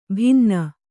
♪ bhinna